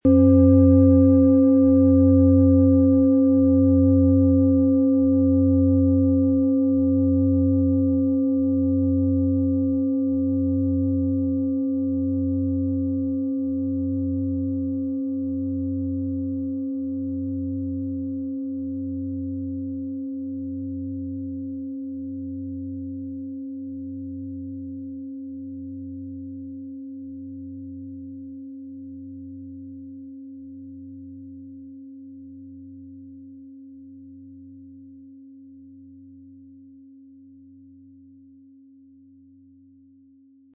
Handgetriebene, tibetanische Planetenklangschale Jupiter.
• Mittlerer Ton: DNA
MaterialBronze